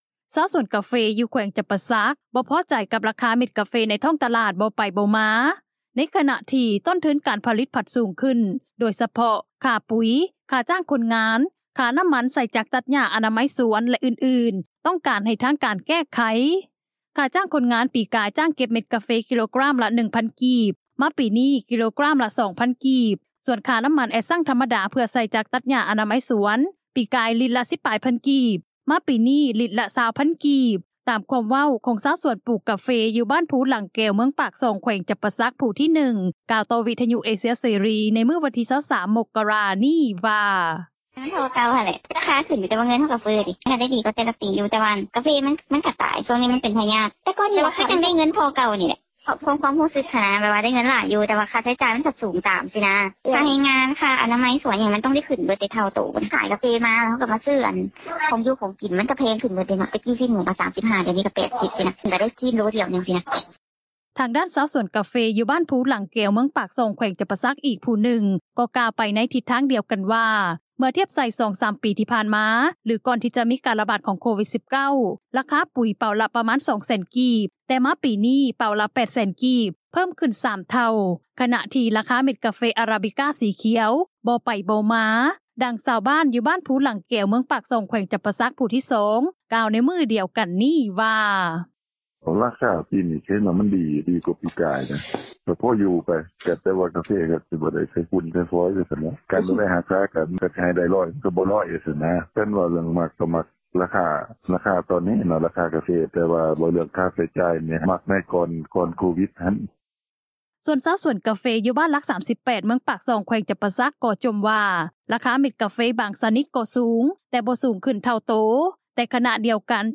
ນັກຂ່າວ ພົລເມືອງ
ດັ່ງຊາວບ້ານ ຢູ່ບ້ານພູຫຼັງແກວ ເມືອງປາກຊ່ອງ ແຂວງຈໍາປາສັກ ຜູ້ທີ 2 ກ່າວໃນມື້ດຽວກັນນີ້ວ່າ: